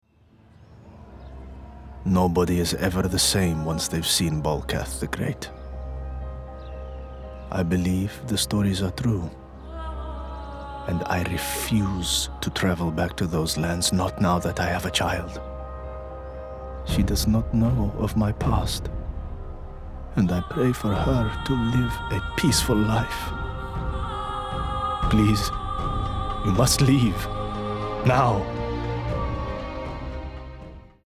20/30's London,
Contemporary/Friendly/Warm
Arab Royalty Machine World Captain Tech Agent – American